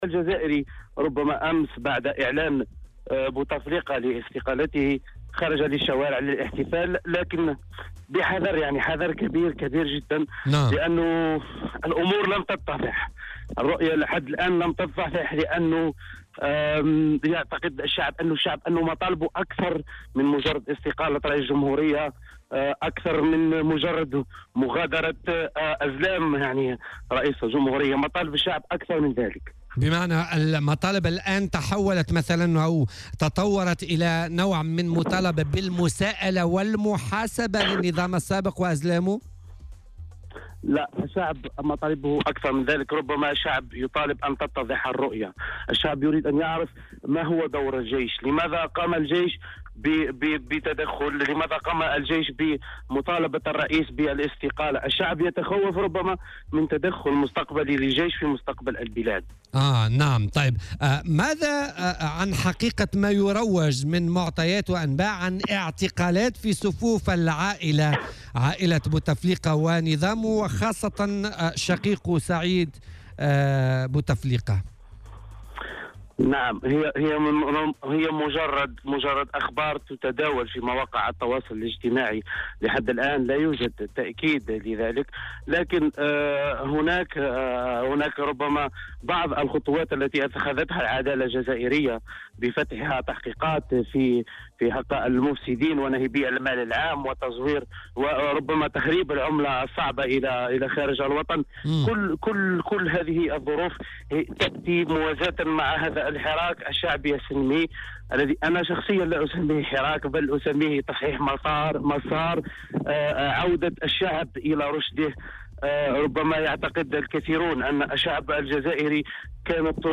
وأضاف في مداخلة له اليوم في برنامج "بوليتيكا" أن الشعب الجزائري خرج أمس للاحتفال بعد الإعلان عن استقالة بوتفليقة لكن "بحذر شديد".